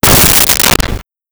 Door Close Slam
Door Close Slam.wav